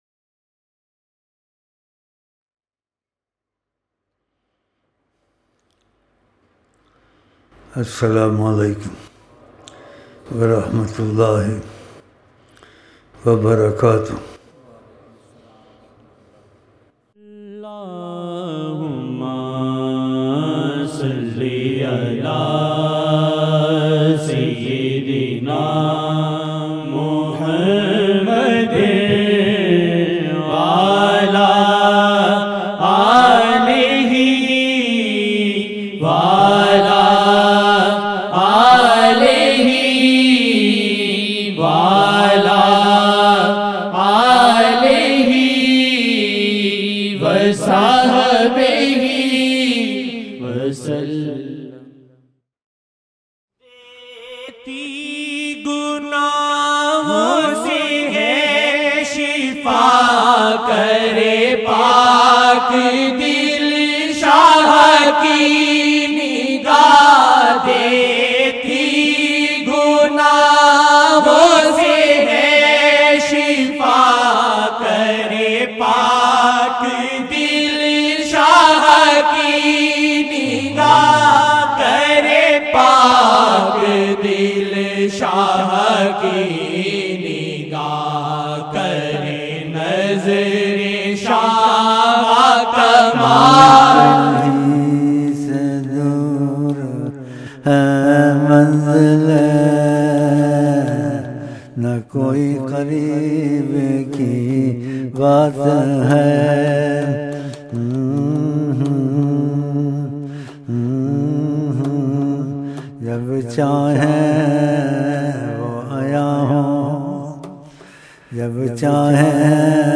5-March-2012-Zohar-11-Rabi-Ul-Sani-Mehfil-Zaruri-Tarbiyat-Wapsi-AAP-SAW-Ki-Teraf-Say-Salaam-Mubarak-Part-2 VM-0627 05 Mar 2012 New/Daily Taleem